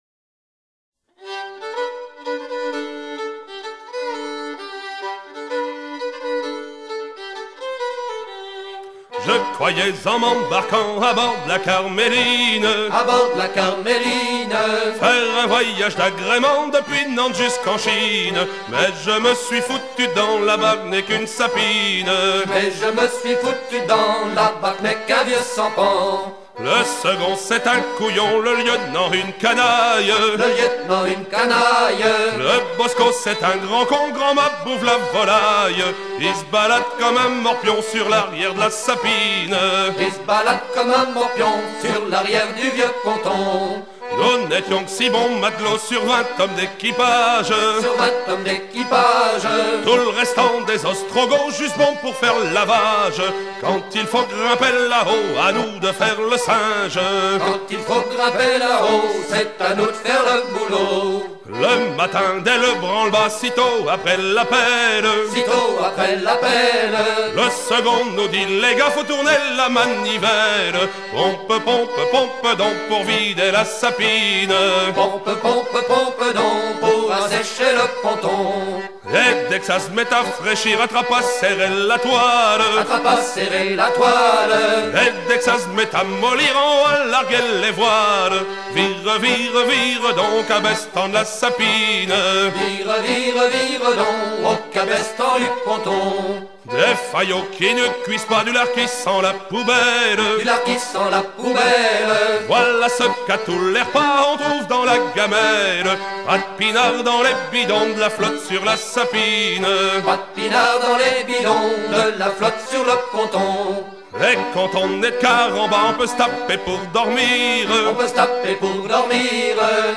Pièce musicale éditée